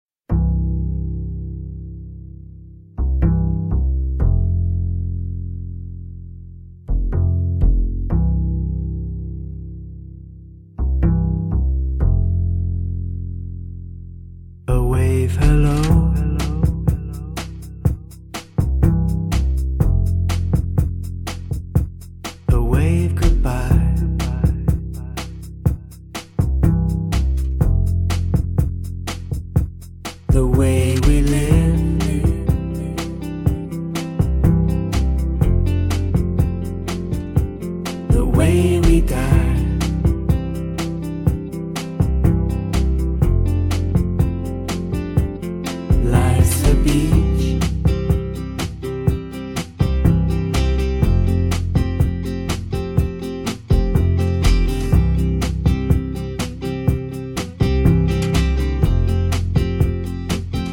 Pop
приятная, чувственная музыка